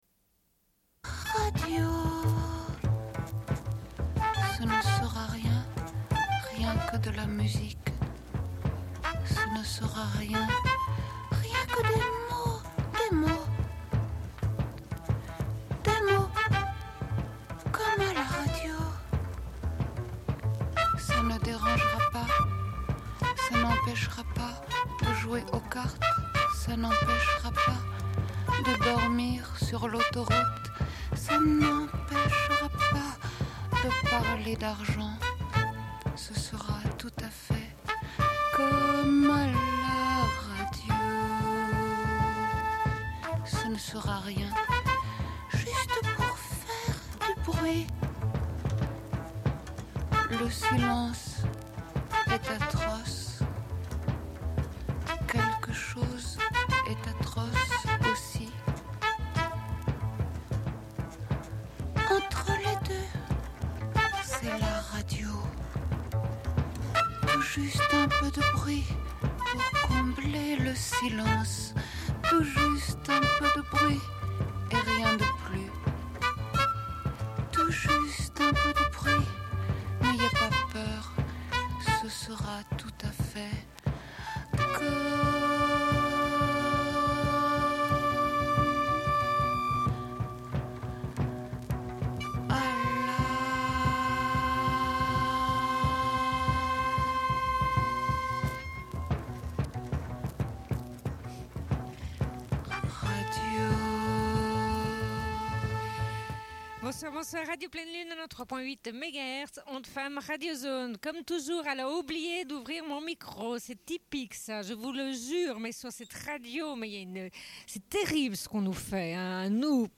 Bulletin d'information de Radio Pleine Lune du 25.11.1992 - Archives contestataires
Une cassette audio, face B31:29